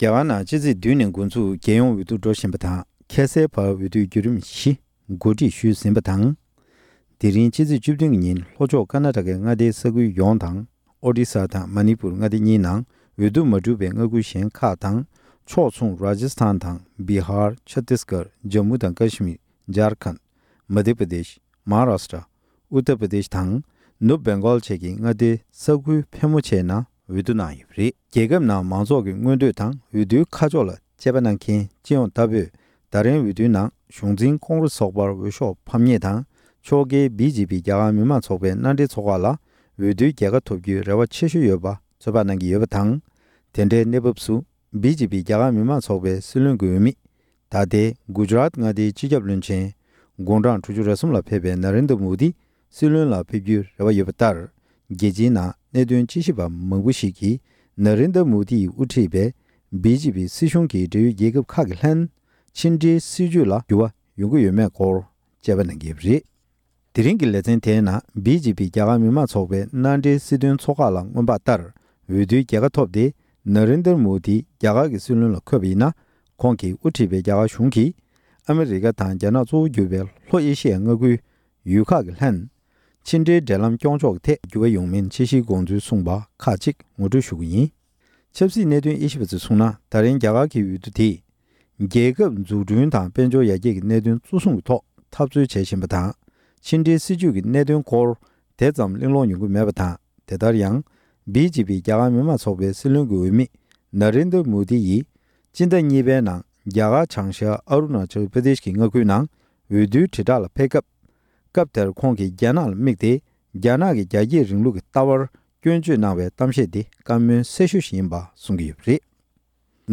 རྒྱ་གར་གྱི་འོས་བསྡུ་ཆེན་མོ། ལེ་ཚན་གཉིས་པ། སྒྲ་ལྡན་གསར་འགྱུར།